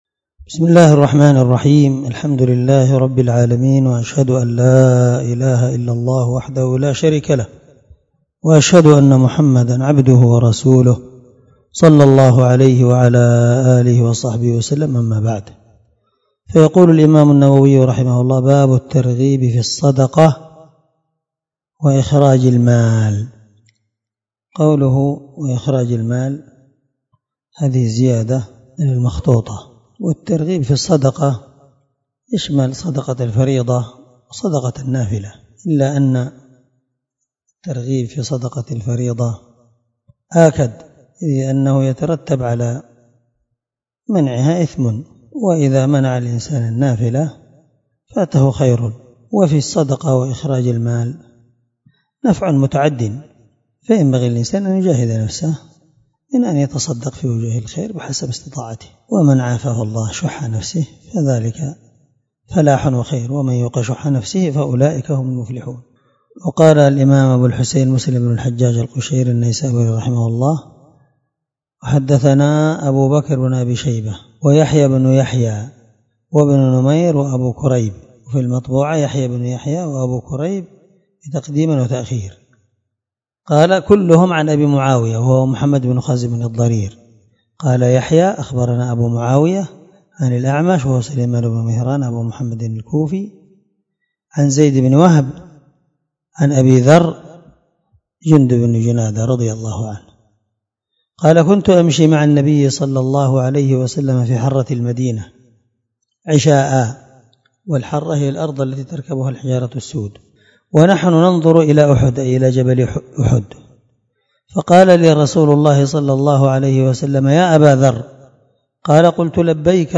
603الدرس 11 من شرح كتاب الزكاة حديث رقم(000) من صحيح مسلم